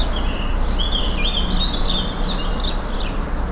• birds singing in garden 3.wav
Blackbirds singing in a city garden with a Tascam DR05.
birds_singing_in_garden_3_c24_hEZ.wav